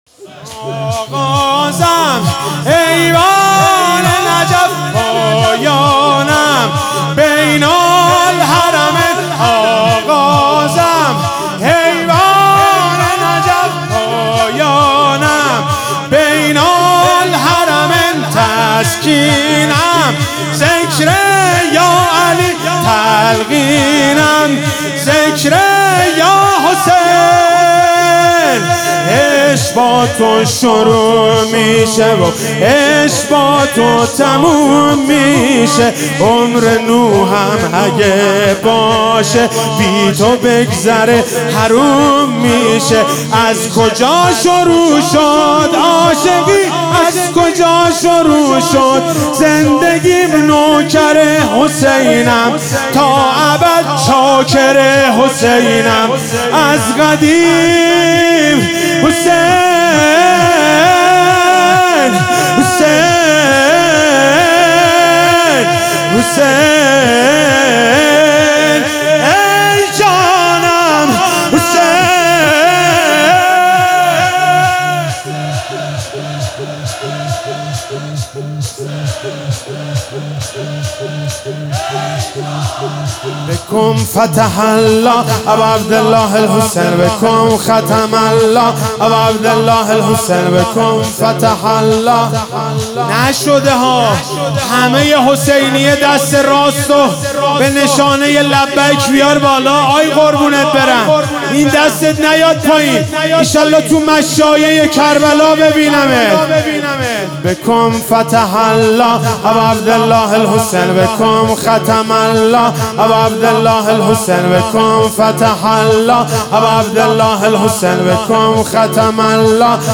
شهادت حضرت رقیه1400 - شور - آغازم ایوان نجف